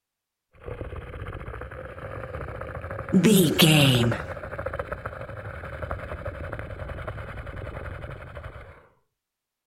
Monster growl slow vibration reptile
Sound Effects
scary
ominous
eerie